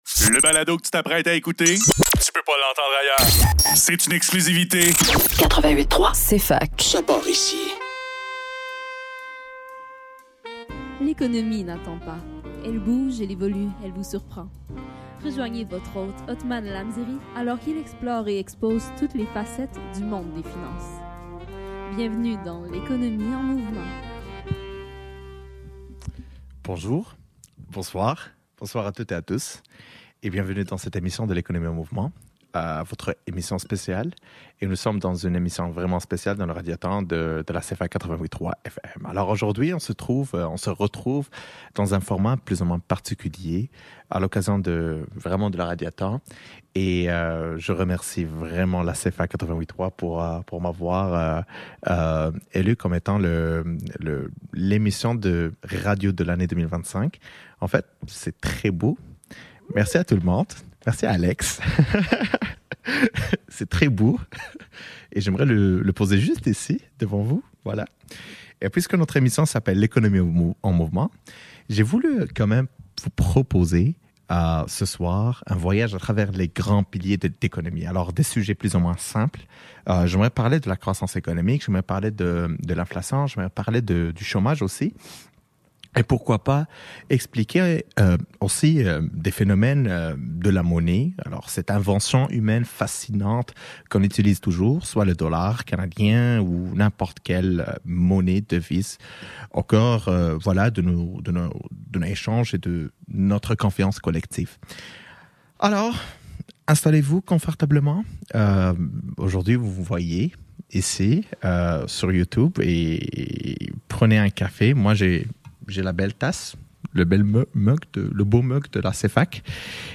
L'économie en mouvement – Croissance économique : de la théorie à la pratique actuelle – émission spéciale du Radiothon, 6 Novembre 2025